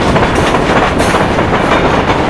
SOUND\TRAIN22.WAV